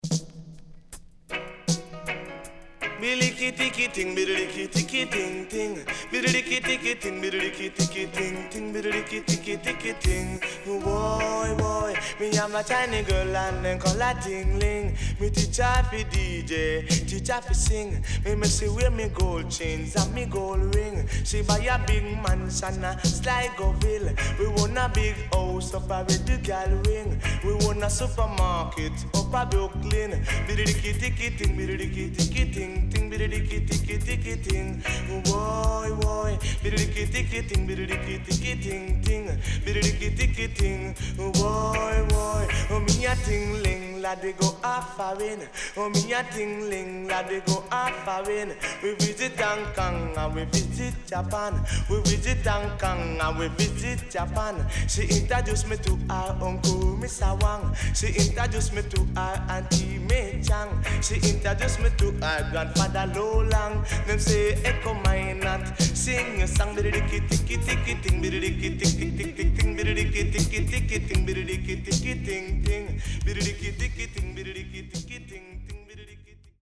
riddim